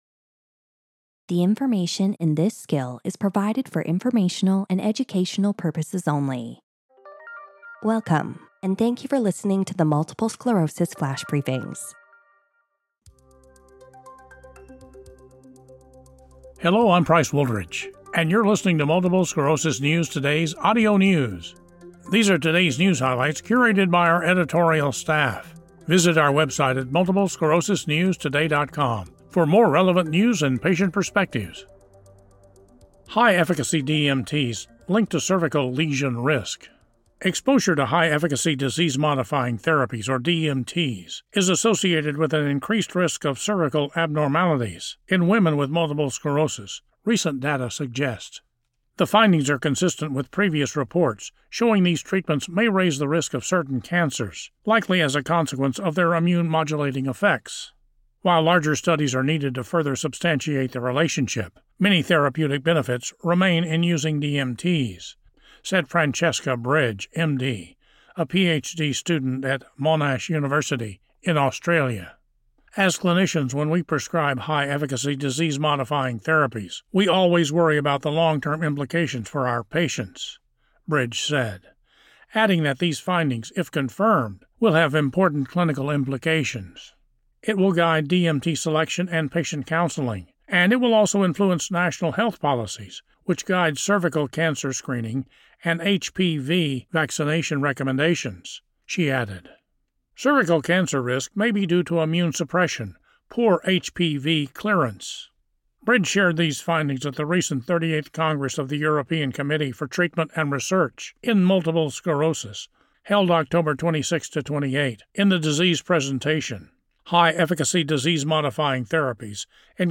reads a news article reporting on how exposure to high-efficacy DMTs is linked to an increased risk of cervical abnormalities in women with MS.